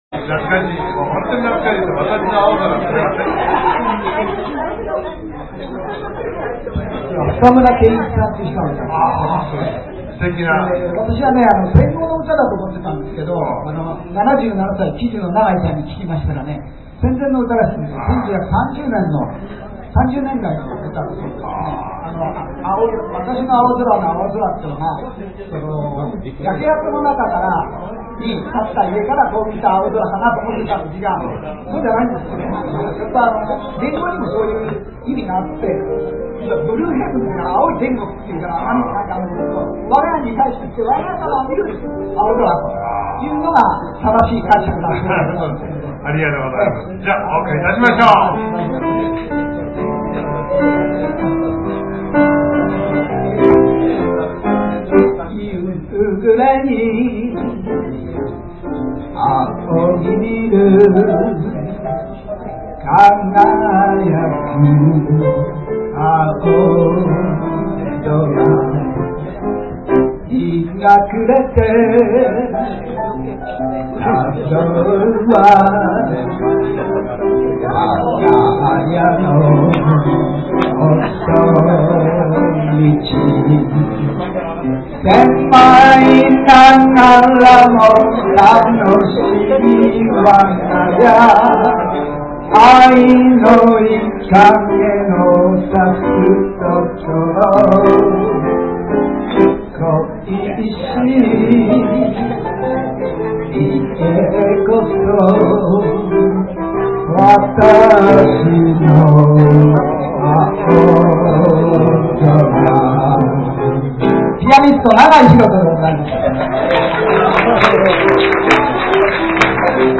ミュージック・レストラン
「アルテリーベ」   ピアノ伴奏での歌唱 「私の青空」